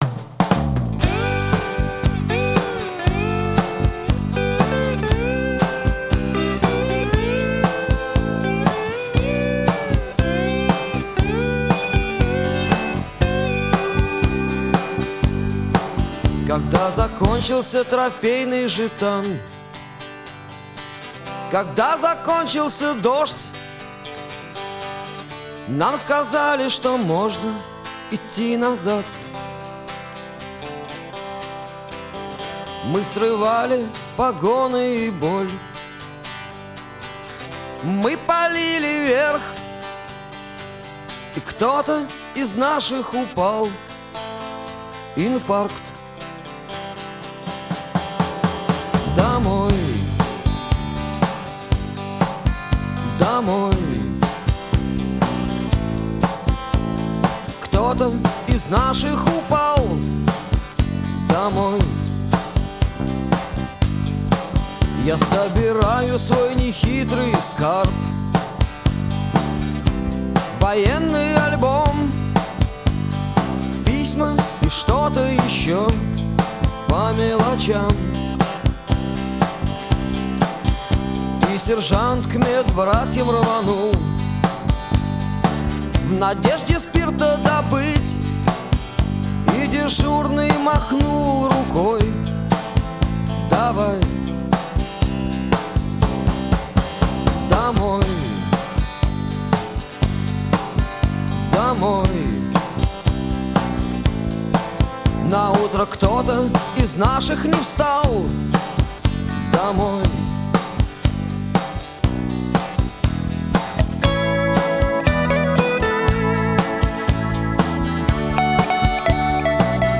Рок музыка Рок Русский рок